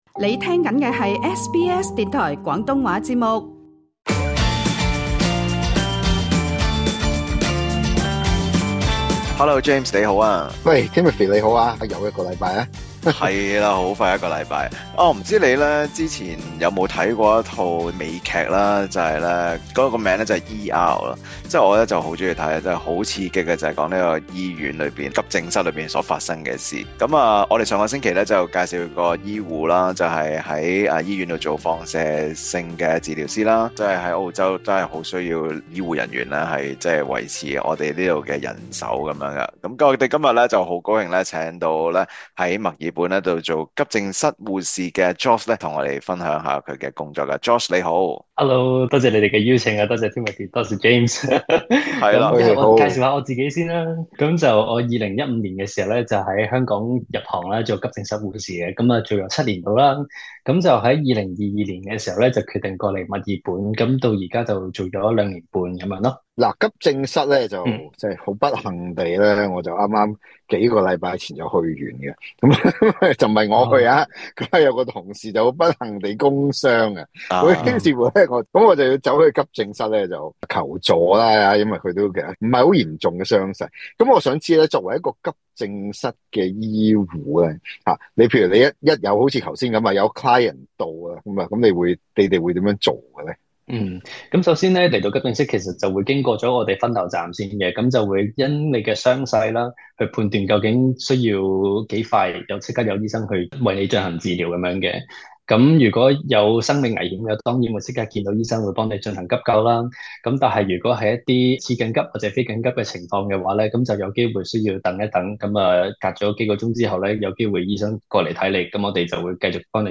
【創業搵食GUIDE】急症室無分晝夜 華人護士親述壓力與使命感